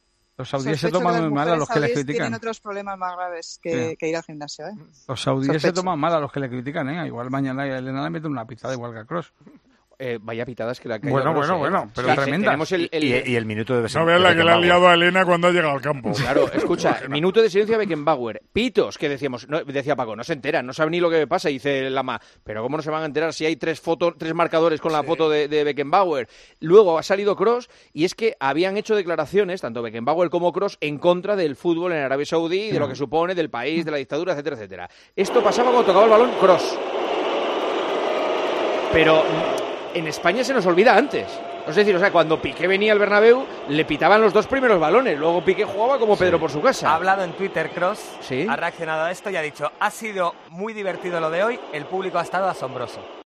La grada del estadio de la semifinal de la Supercopa de España pitó durante el minuto de silencio en honor a la leyenda alemana y durante todo el partido al jugador del Madrid.